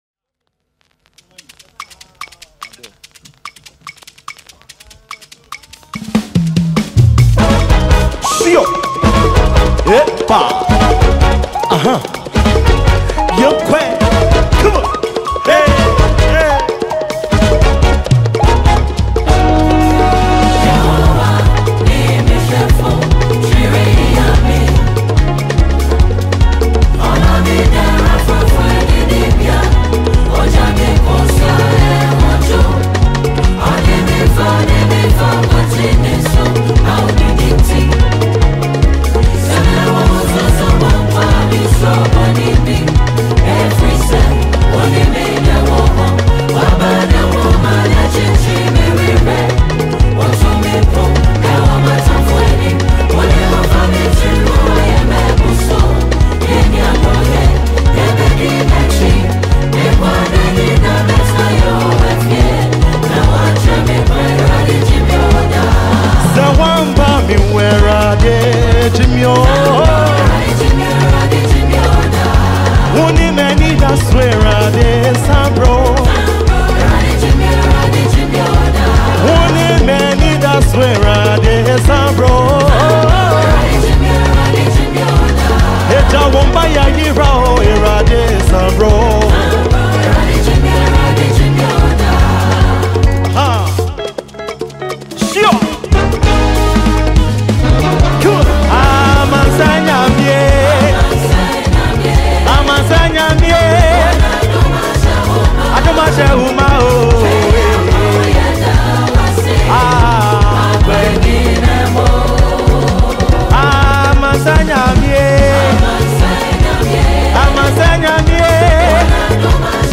a leading gospel music group
Genre: Gospel Release Format Type